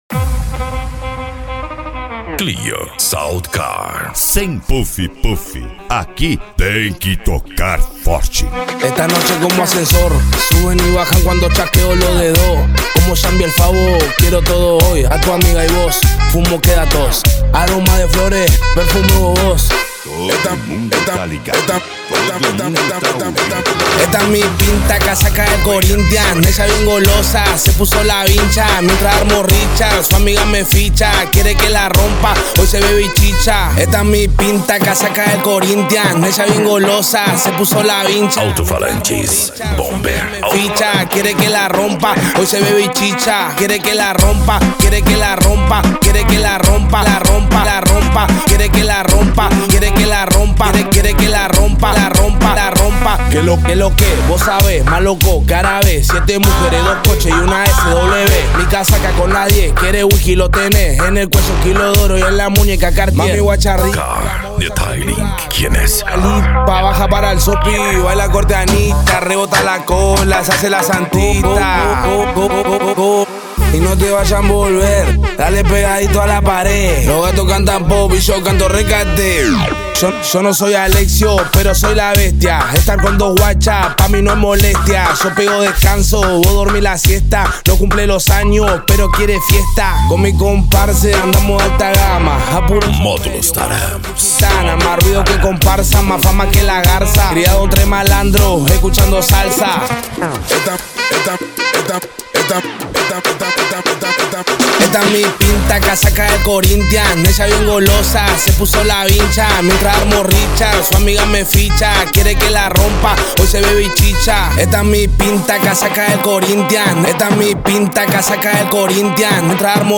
Bass
Eletronica
Modao
Remix